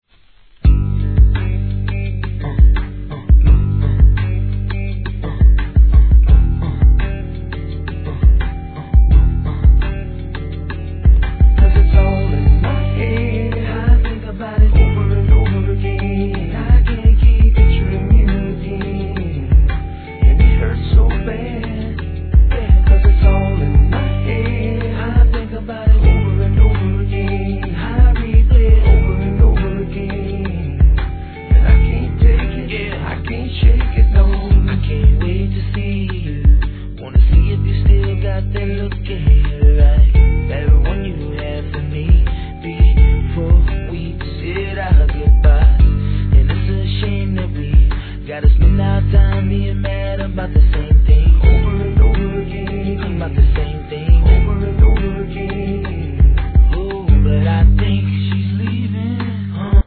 HIP HOP/R&B
穏やかなメロディー・ライン が心地よいトラックに